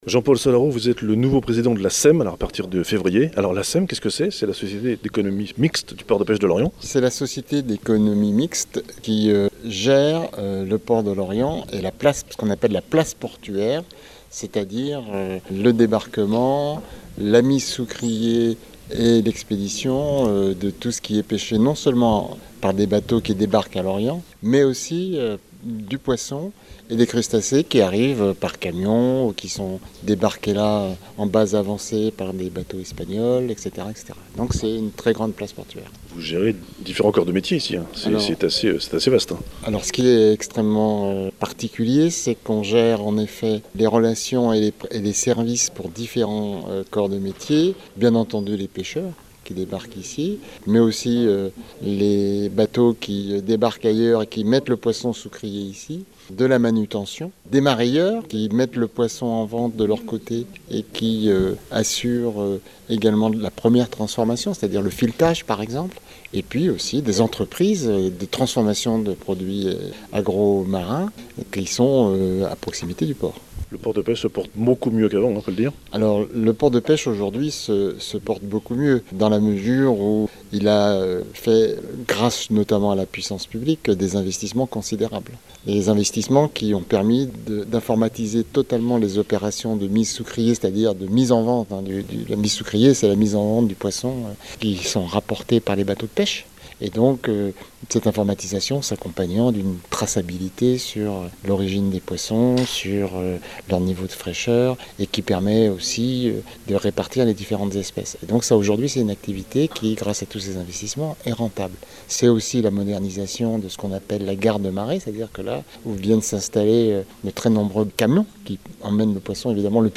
Interview de Jean-Paul Solaro – Nouveau président (également élu à la Ville de Lorient et à Lorient Agglomération)
(Reportage Interviews Radio Korrigans, Radio Balises, Radio Larg…)